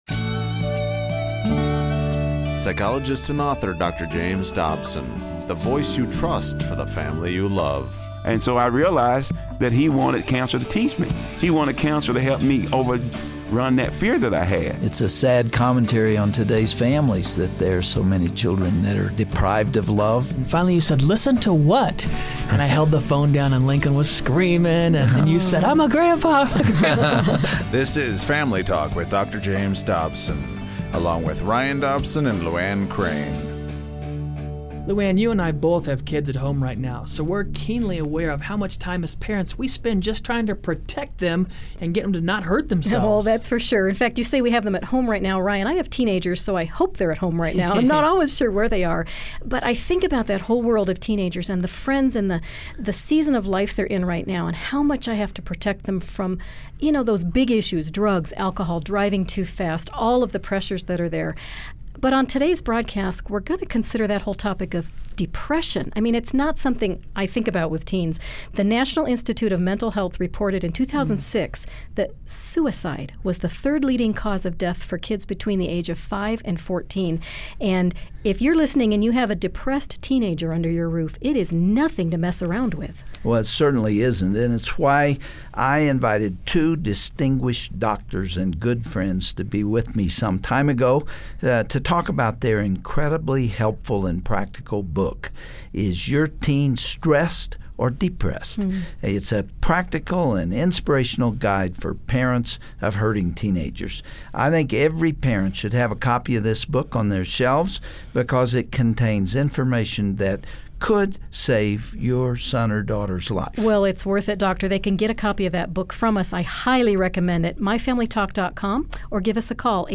Don't miss their enlightening conversation!